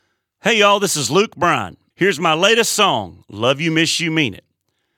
LINER Luke Bryan (LYMYMI) 3